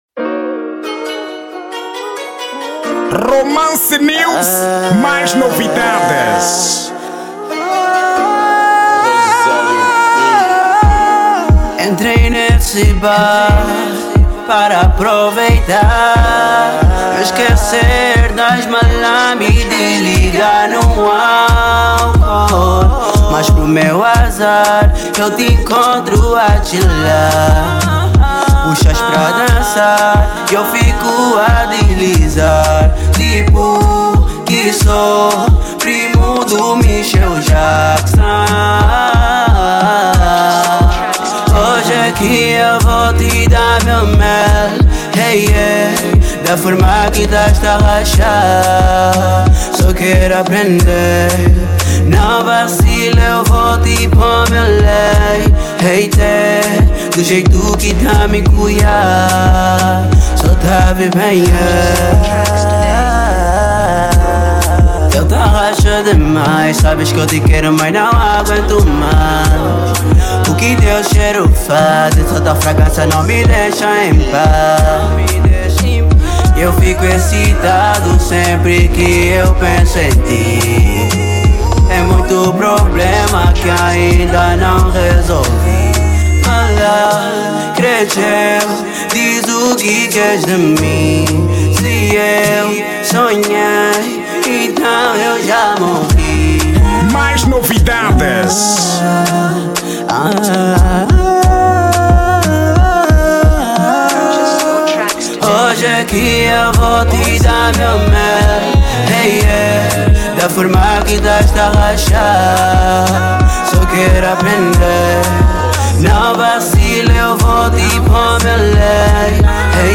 Estilo: Zouk